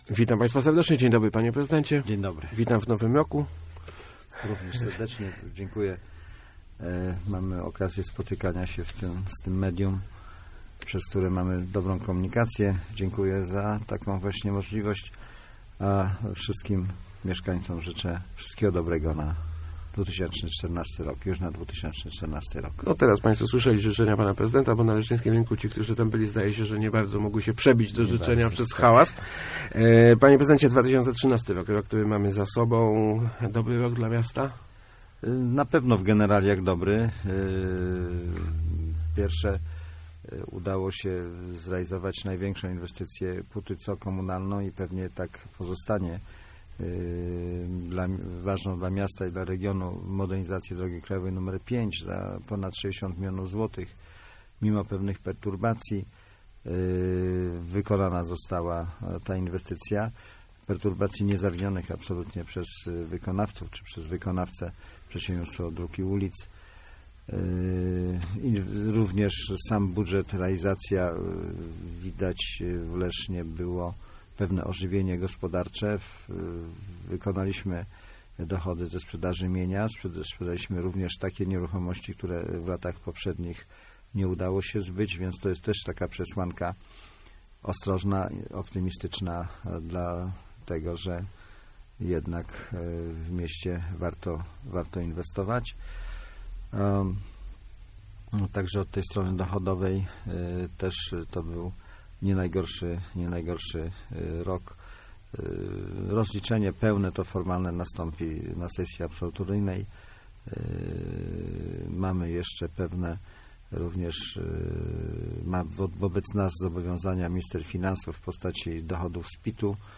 Gościem Kwadransa był prezydent Tomasz Malepszy. ...